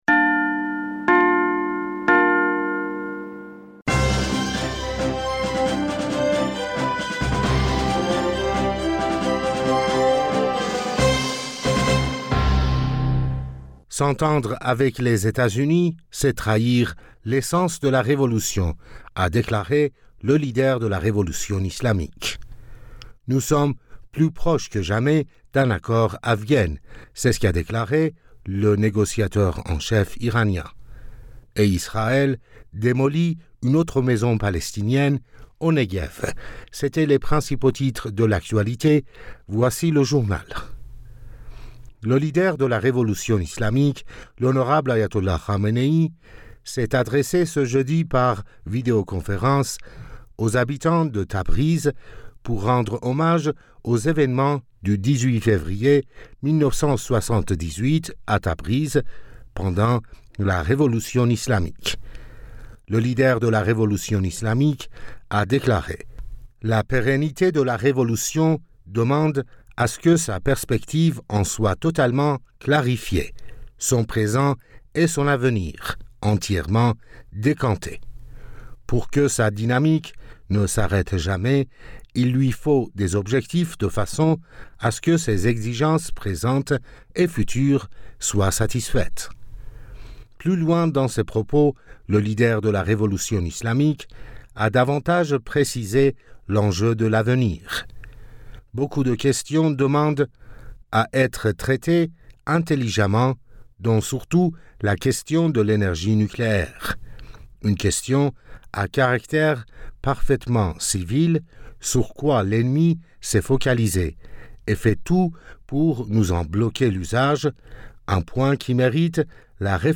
Bulletin d'information Du 17 Fevrier 2022